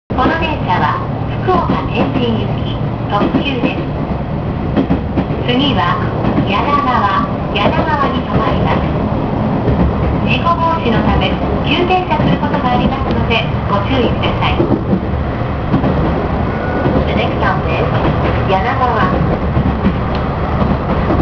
・8000形 車内自動放送